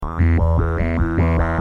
Oberheim - Matrix 1000 9